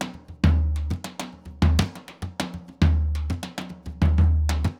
Surdo Candombe 100_1.wav